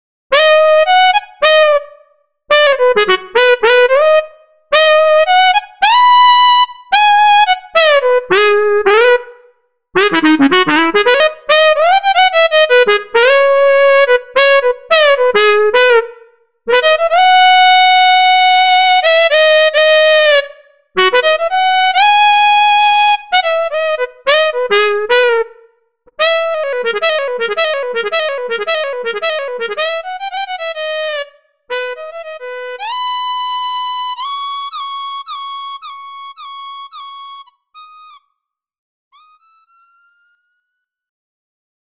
HALion6 : sax
Bari Sax Swell